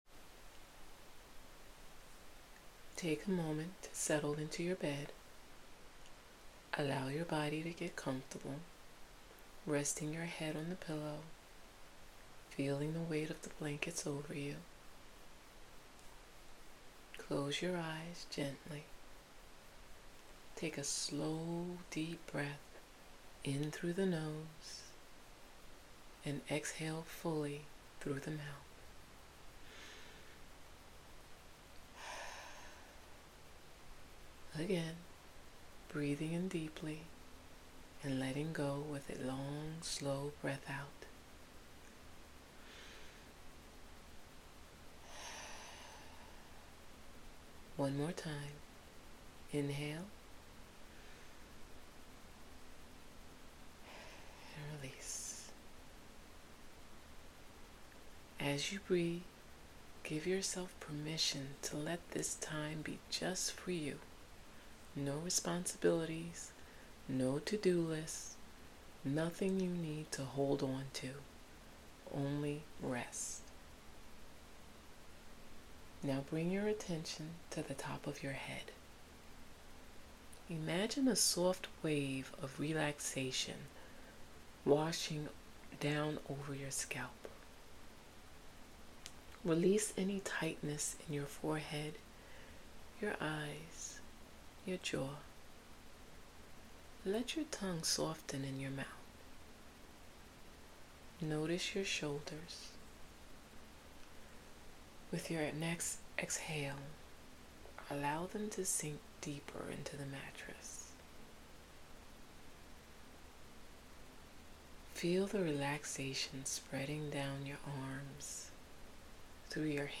Sample Guided Sleep Meditation
sample-guided-sleep-meditation-AGBz7PJQM1uQbpk0.mp3